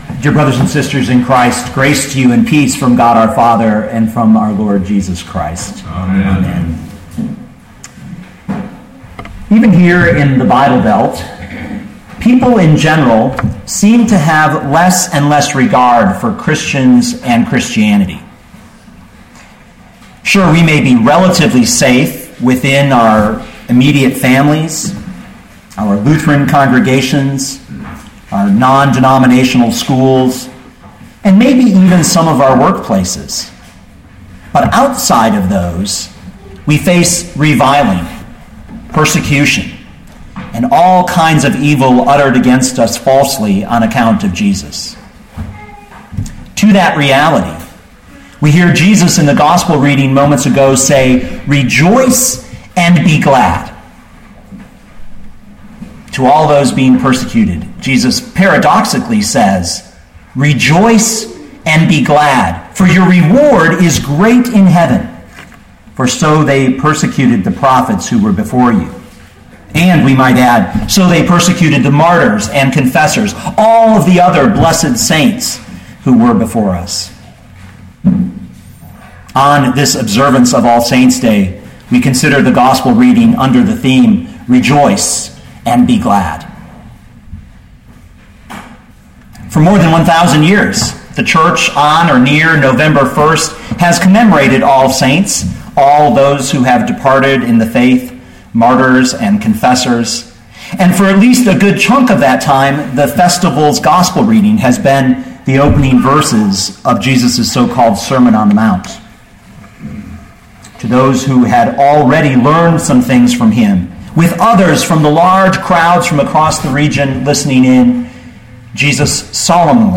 2013 Matthew 5:1-12 Listen to the sermon with the player below, or, download the audio.